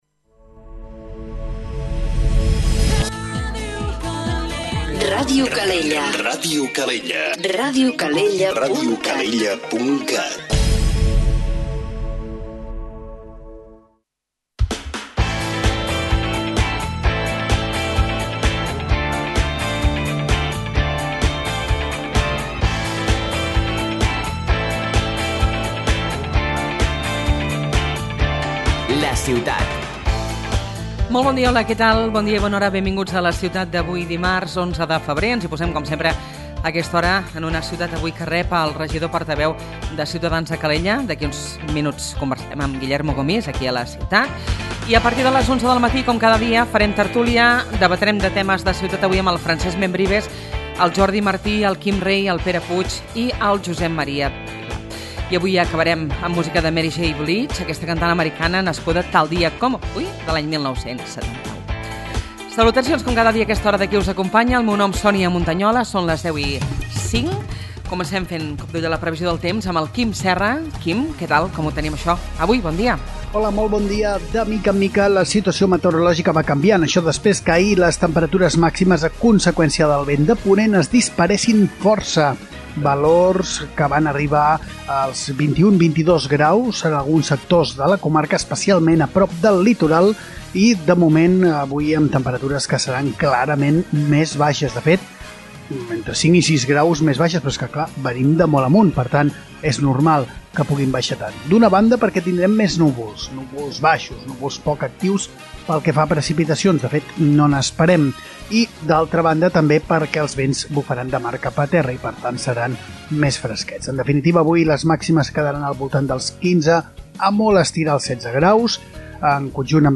Aquest matí ha passat pels nostres estudis el regidor portaveu de Ciutadans a Calella, Guillermo Gomis. L’estat actual de la nostra ciutat, l’aliança que diuen els partits de l’oposició que té amb el govern municipal, l’aparcament o les banderes, són alguns dels temes que hem abordat.